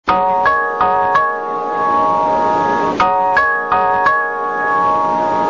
※音質はテレコで収録し、なおかつ通勤時間帯(18時〜19時）に収録したためかなり悪く、音割れしております。
旧・２番線 発車メロディ− (27KB/5秒)